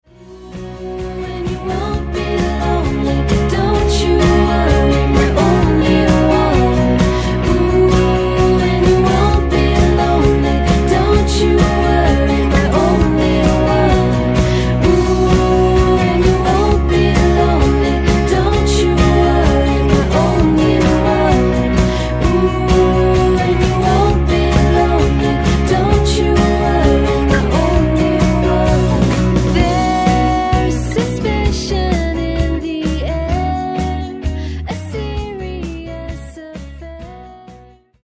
インディポップ/ワールド